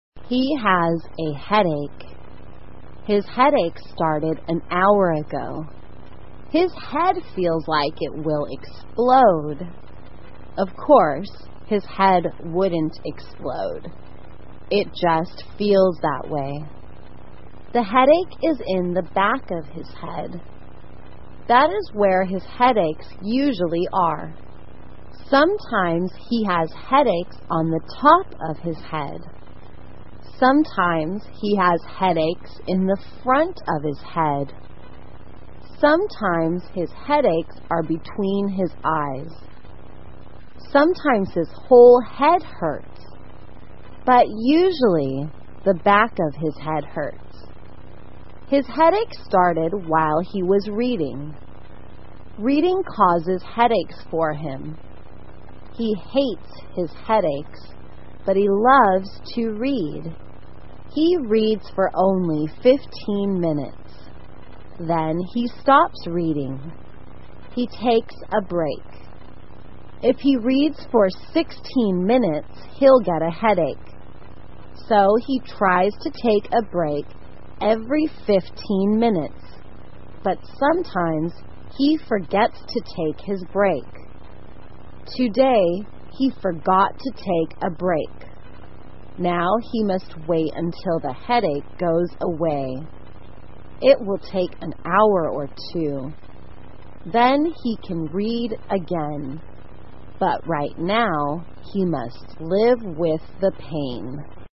慢速英语短文听力 头疼 听力文件下载—在线英语听力室